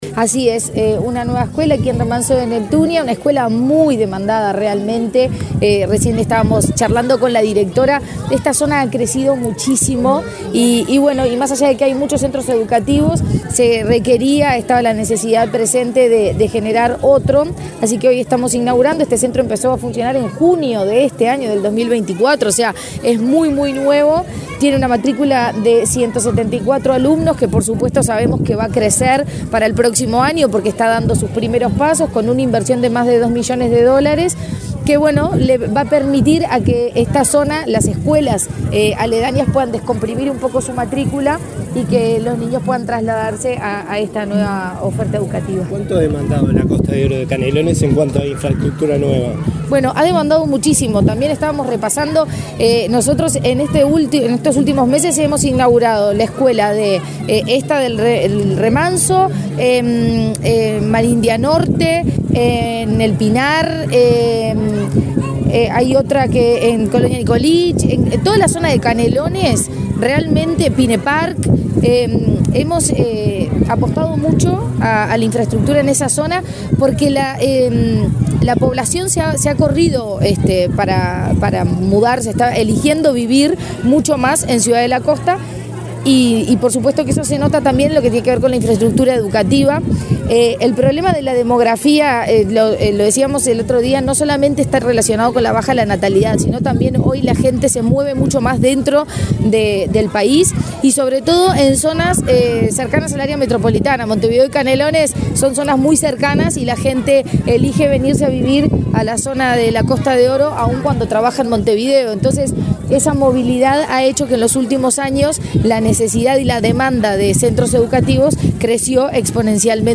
Declaraciones de la presidenta de ANEP, Virginia Cáceres
Declaraciones de la presidenta de ANEP, Virginia Cáceres 22/10/2024 Compartir Facebook X Copiar enlace WhatsApp LinkedIn La directora general de Educación Inicial y Primaria, Olga de las Heras, y la presidenta de la Administración Nacional de Educación Pública (ANEP), Virginia Cáceres, participaron en la inauguración de la escuela n.° 318, de doble turno, de Remanso de Neptunia, departamento de Canelones. Antes, Cáceres dialogó con la prensa.